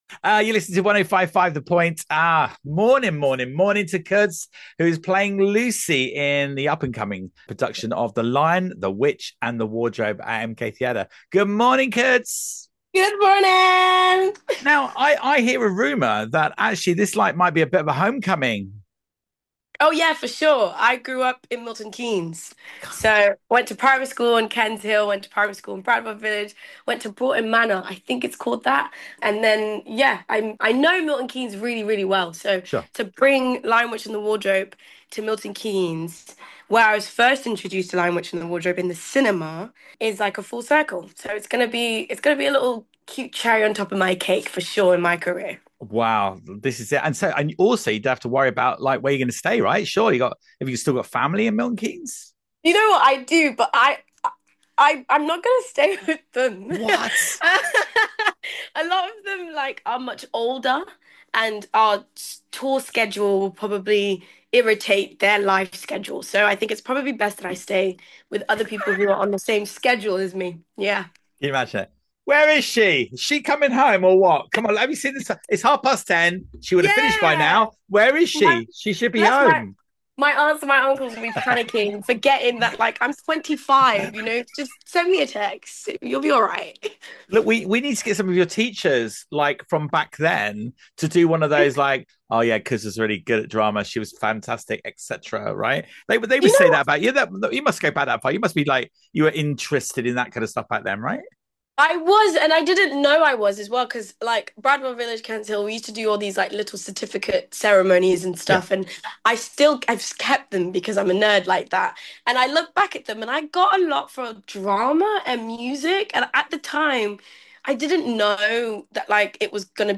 Friends! The Musical Parody Milton Keynes Theatre This Week - Review and Interview Attached